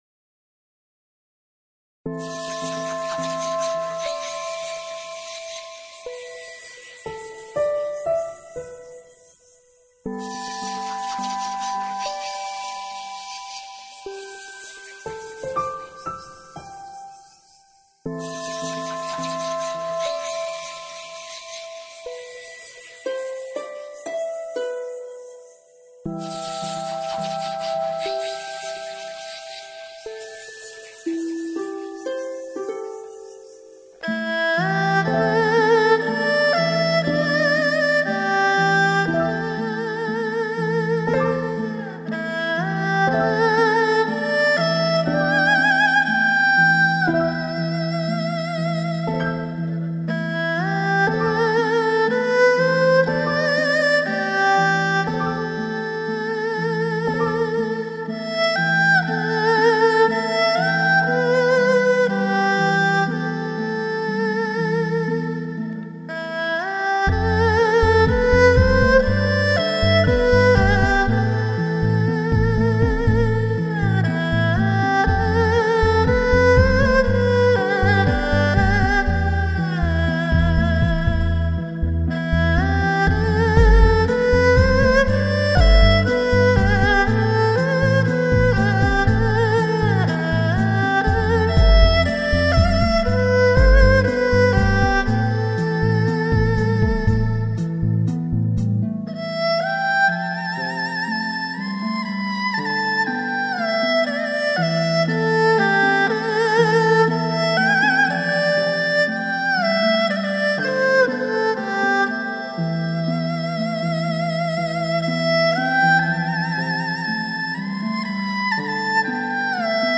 古典民乐 你是第9191个围观者 0条评论 供稿者： 标签：,